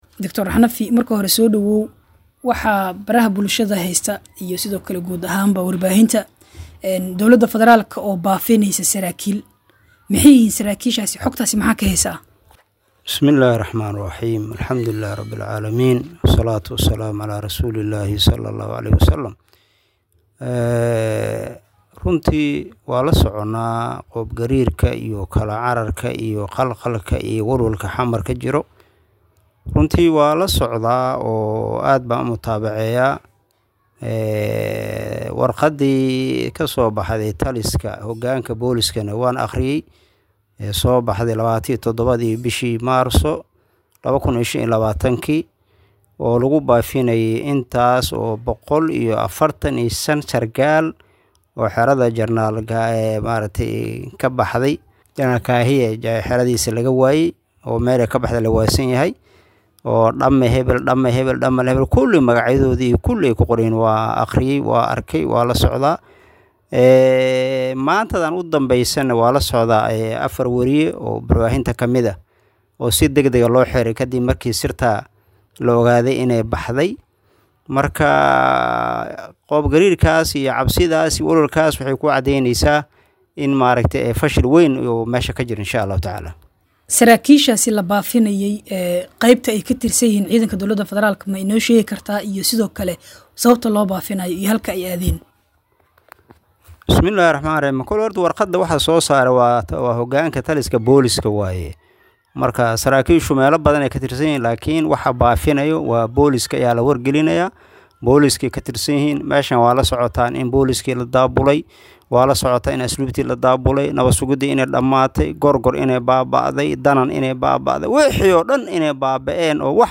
Halkaan-ka-Degso-Wareysiga-1.mp3